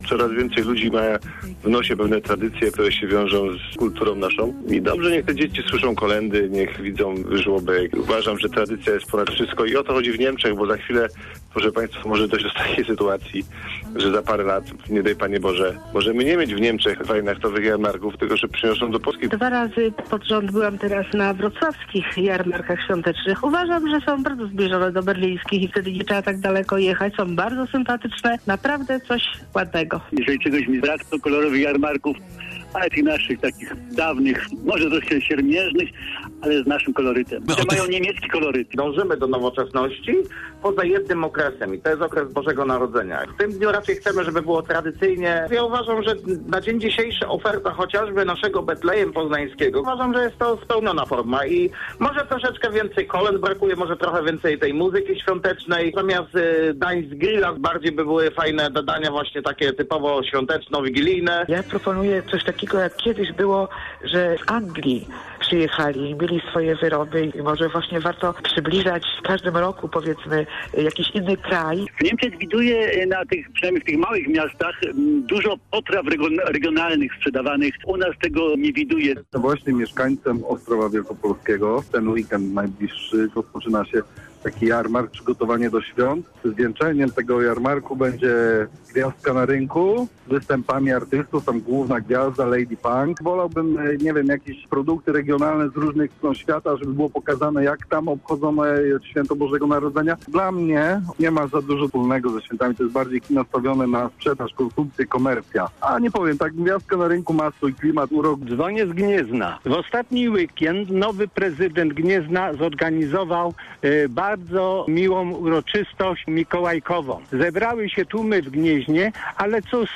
m5wp2uyyh8ph8p8_skrot-audycji-o-jarmarkach-swiatecznych.mp3